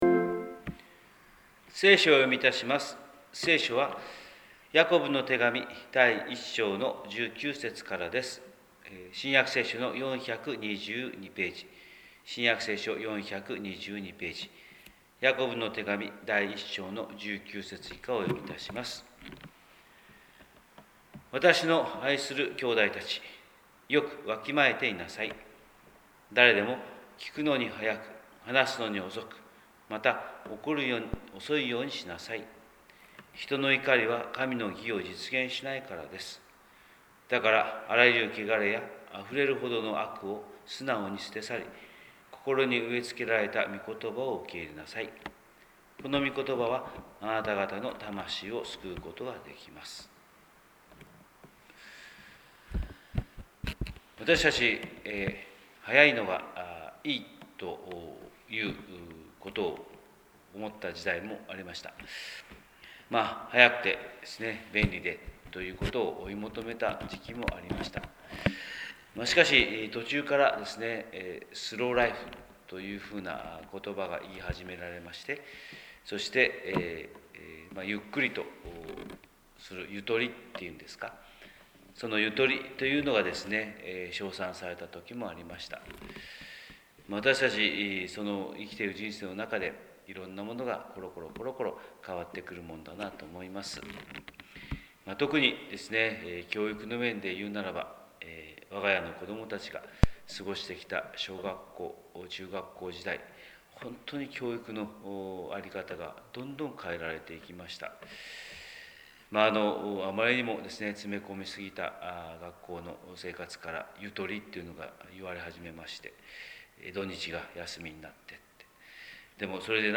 神様の色鉛筆（音声説教）: 広島教会朝礼拝241113
広島教会朝礼拝241113